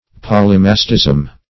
Search Result for " polymastism" : The Collaborative International Dictionary of English v.0.48: Polymastism \Pol`y*mas"tism\, n. [Poly- + Gr.